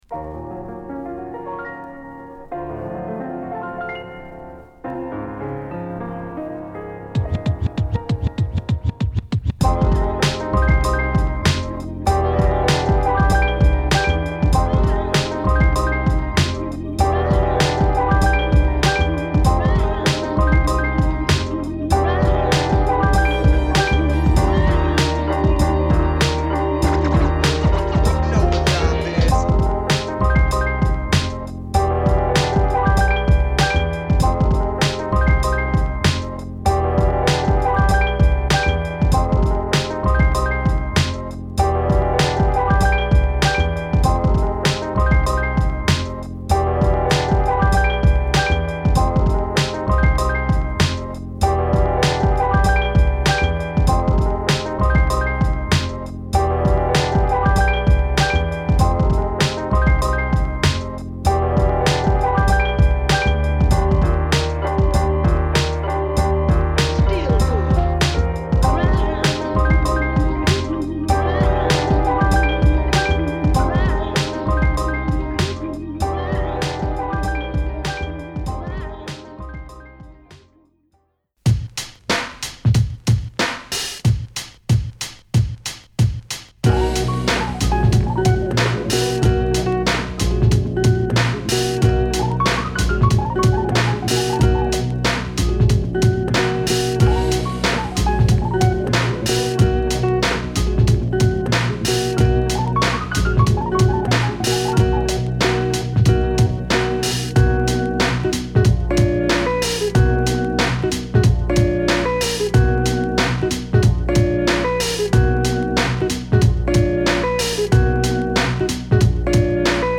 心地よいメロウトラックを満載。